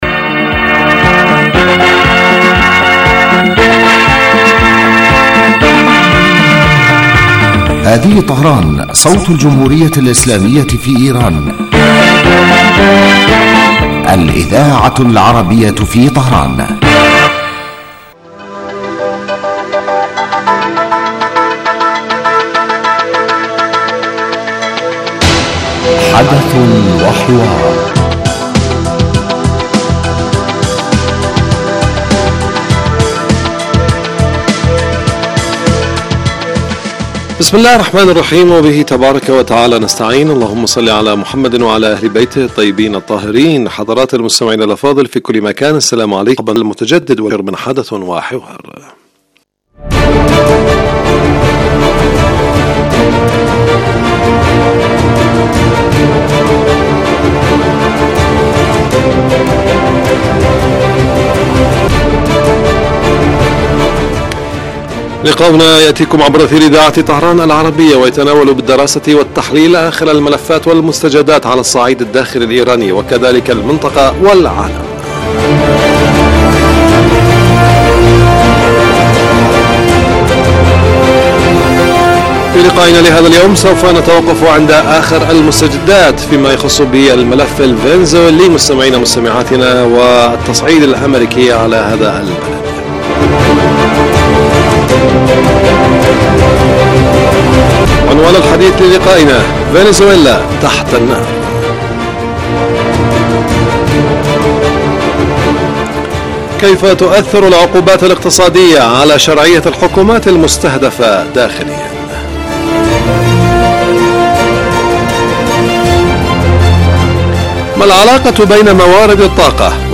يبدأ البرنامج بمقدمة يتناول فيها المقدم الموضوع ثم يطرحه للنقاش من خلال تساؤلات يوجهها للخبير السياسي الضيف في الاستوديو.
ثم يتم تلقي مداخلات من المستمعين هاتفيا حول الرؤى التي يطرحها ضيف الاستوديو وخبير آخر يتم استقباله عبر الهاتف ويتناول الموضوع بصورة تحليلية.